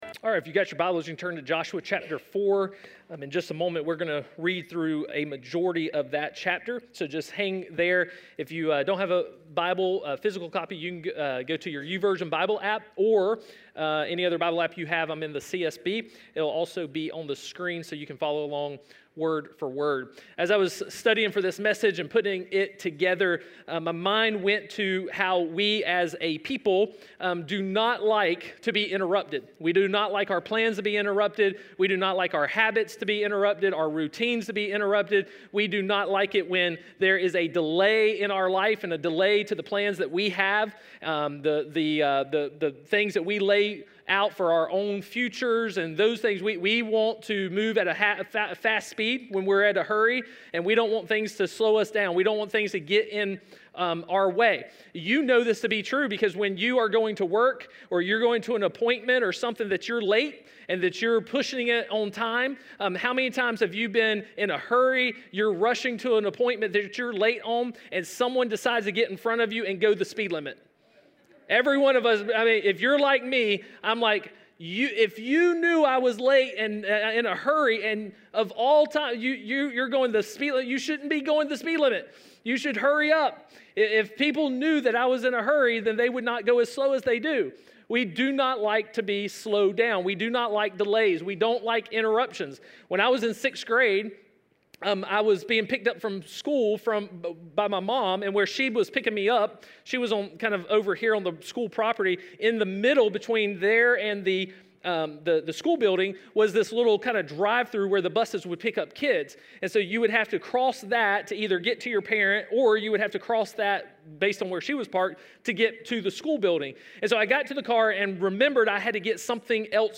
Solo sermons are stand alone sermons that do not belong to a particular series, or they are preached by a guest preacher.
Podcast-12th-Birthday-Service.mp3